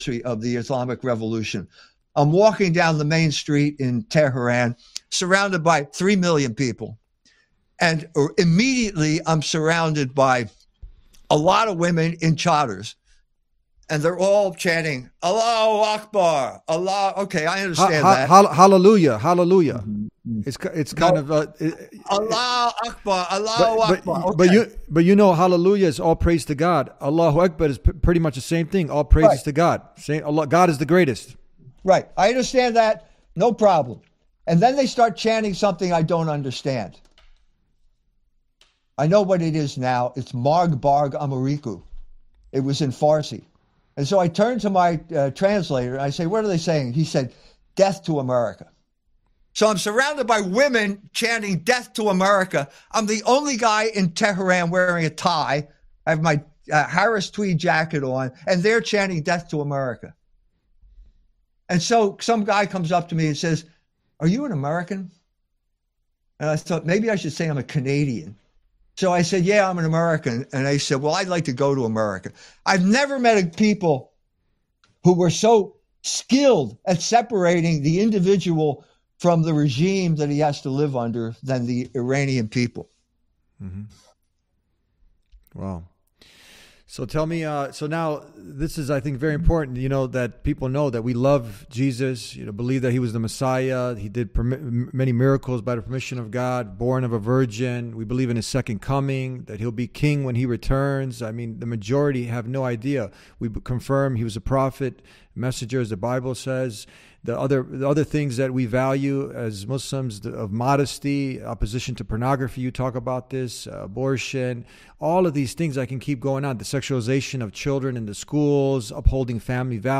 Catholic Muslim conversation